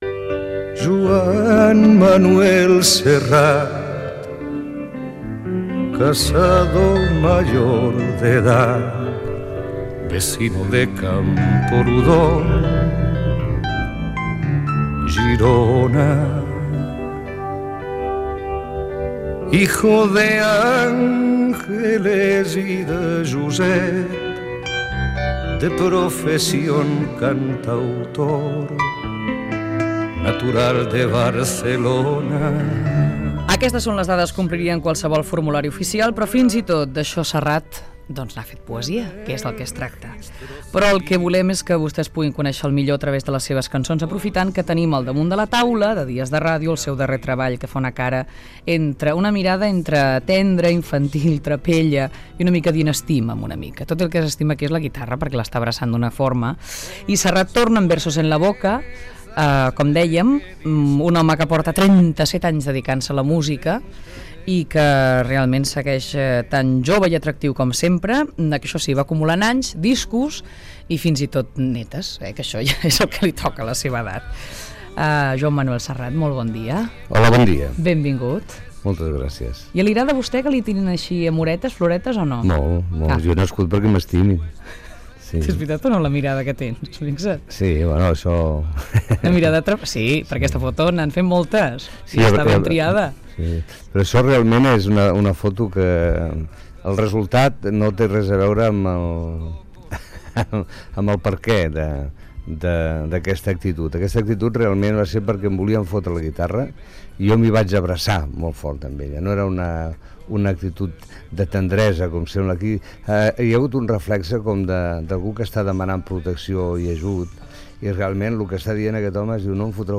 Fragment extret d'una entrevista al cantautor Joan Manuel Serrat pel seu disc "Versos en la boca".
Entreteniment
FM